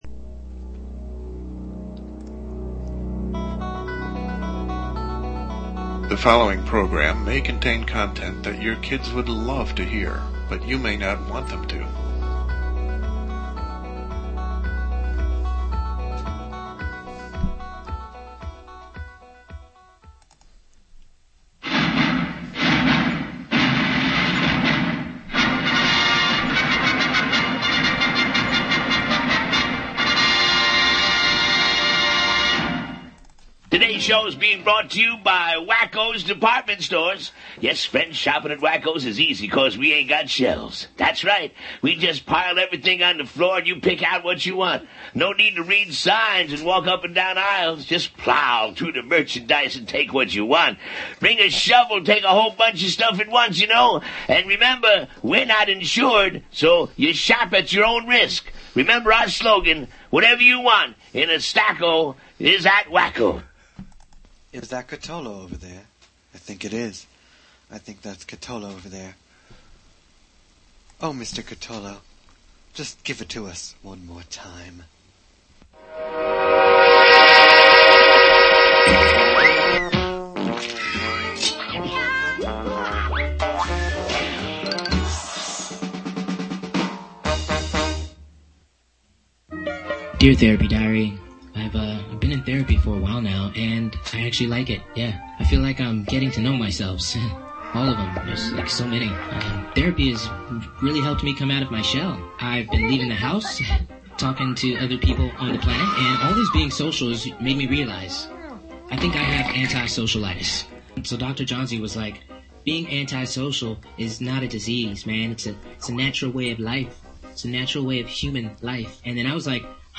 His appearance launches the first conversation of 2014 between the iconic doctor and the idiot host.